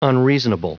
Prononciation audio / Fichier audio de UNREASONABLE en anglais
Prononciation du mot unreasonable en anglais (fichier audio)
unreasonable.wav